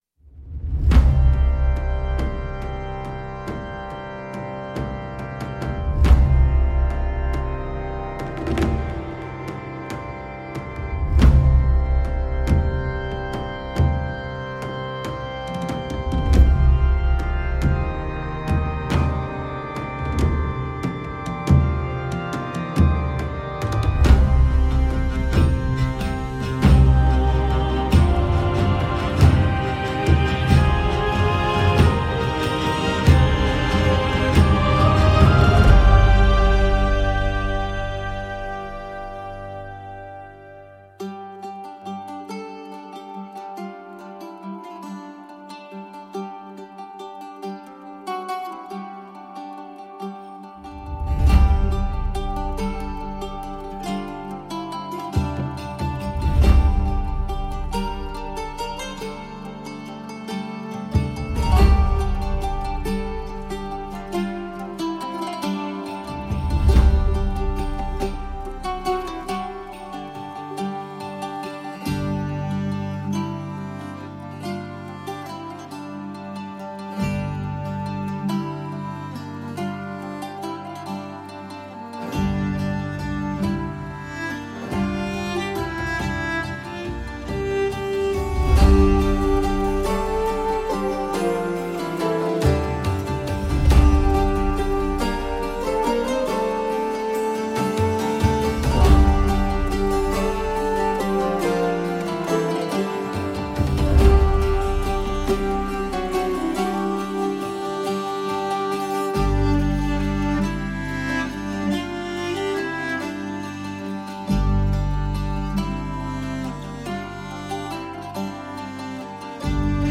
Le tableau est évocateur, oppressant et captivant.